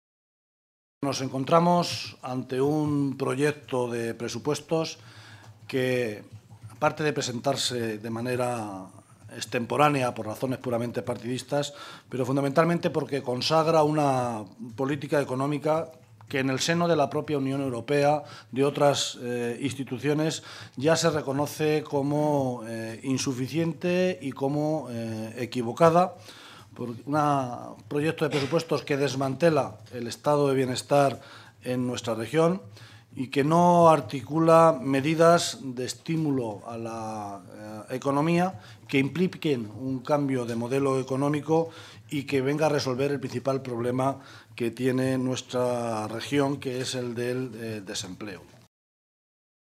José Luis Martínez Guijarro, portavoz del Grupo Parlamentario Socialista
Cortes de audio de la rueda de prensa